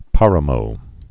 (pärə-mō, părə-) or pá·ra·mo (pärə-)